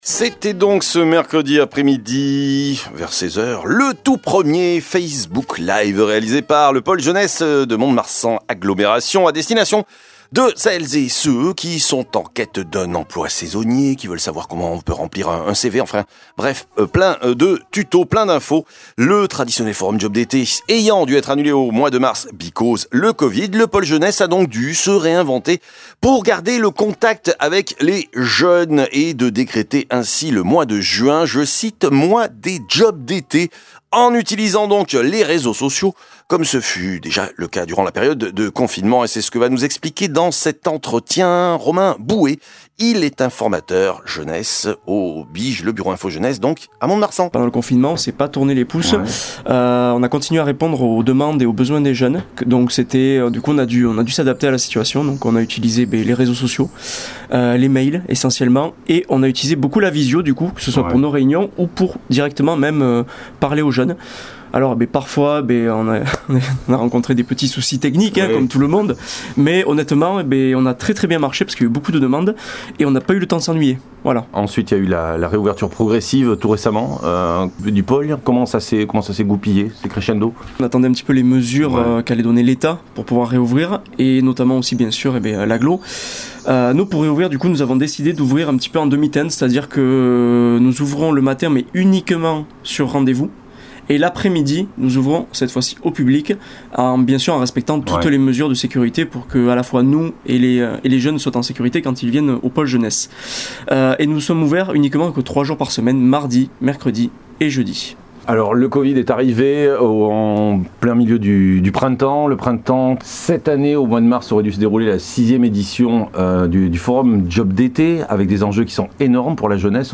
ITV_JUIN_MOIS_DES_JOBS_D_ETE_juin2020.mp3